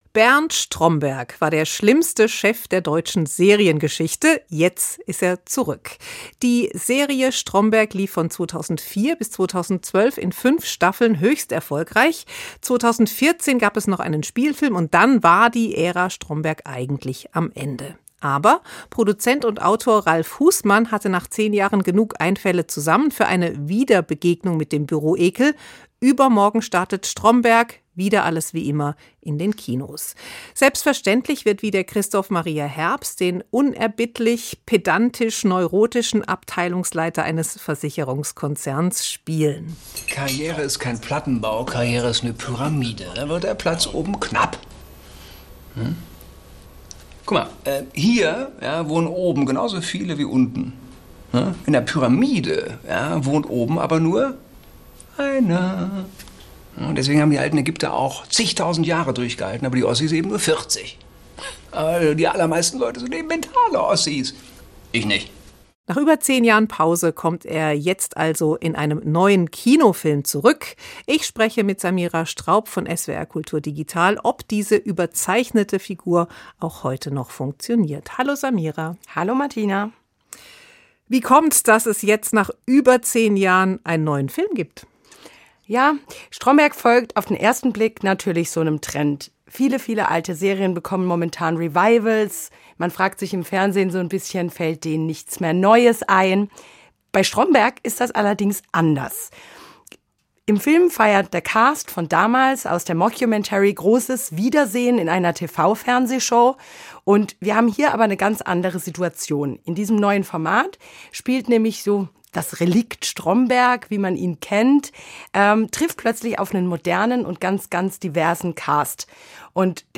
Gespräch Stromberg kehrt zurück auf die große Leinwand